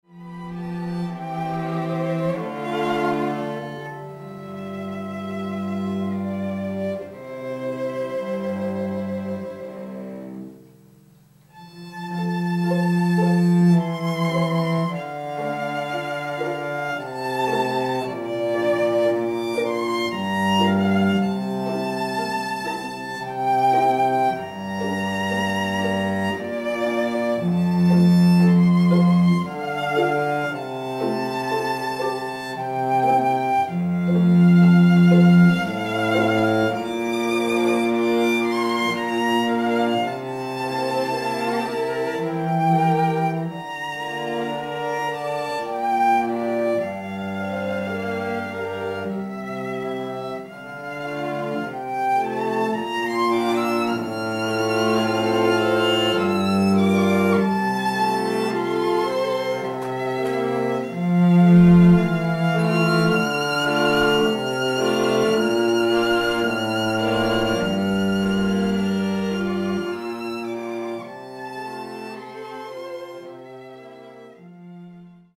Comprised of four professional musicians, this highly-respected string quartet have been performing together for over 20 years, and the sophisticated sounds of their beautifully-harmonised strings create a magical, romantic atmosphere for wedding ceremonies or receptions.
• Professional string quartet